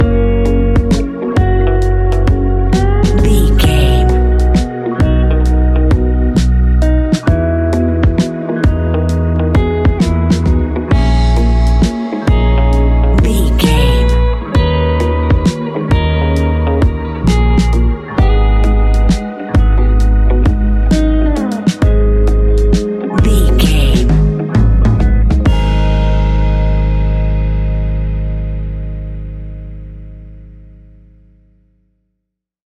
Ionian/Major
G♭
chilled
laid back
Lounge
sparse
new age
chilled electronica
ambient
atmospheric
morphing